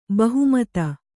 ♪ bahu mata